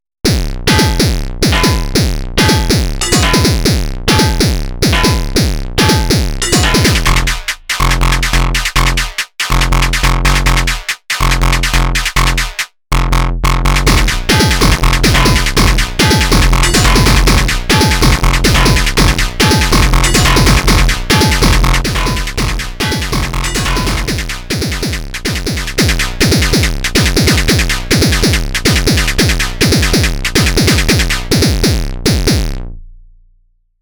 It’s the SWAVE-SAW machine in extreme settings.
A doodle from a minute ago.
Second one is banger, like early AFX tracks